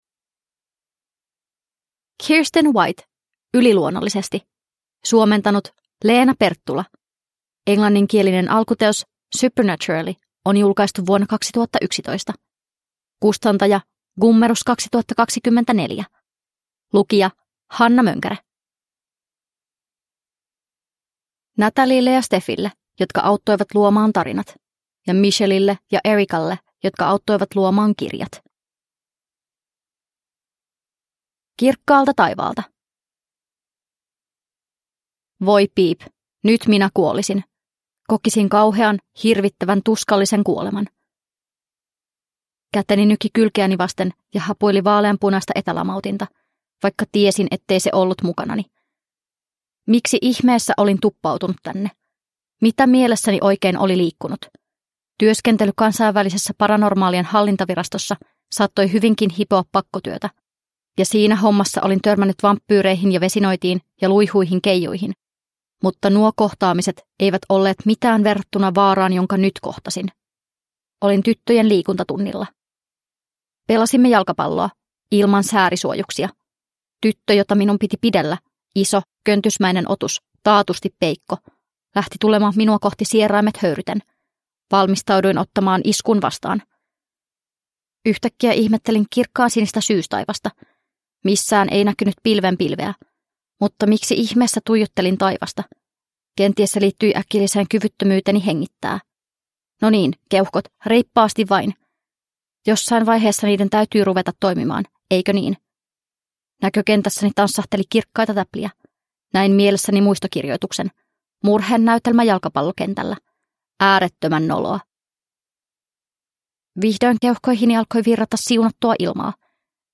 Yliluonnollisesti – Ljudbok